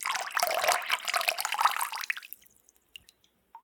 water-01
bath bathroom bathtub bubble burp click drain drip sound effect free sound royalty free Nature